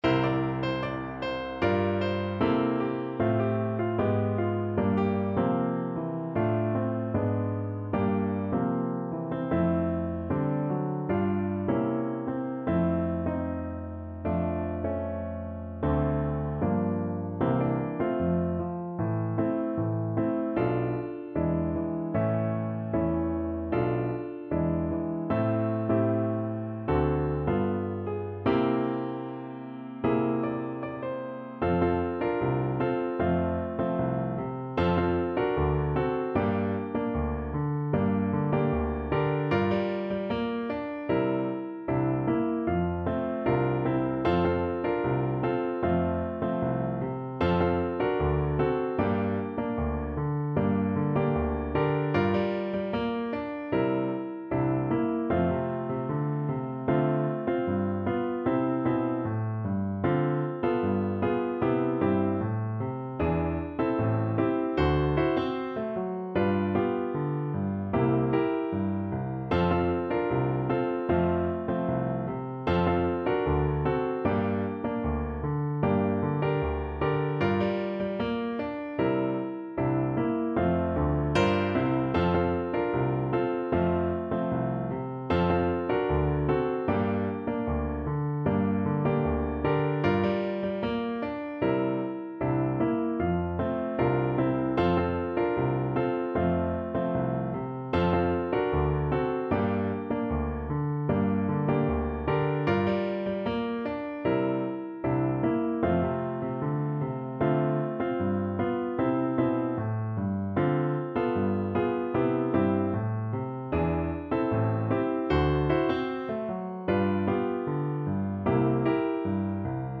Pop (View more Pop Flute Music)